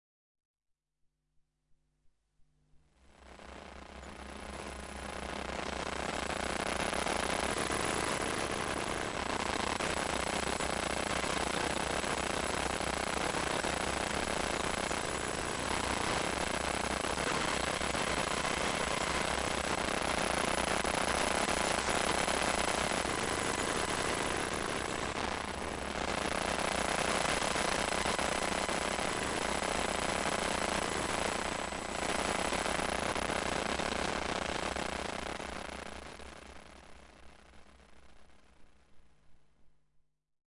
电磁场 " 触控板
描述：使用Zoom H1和电磁拾音器录制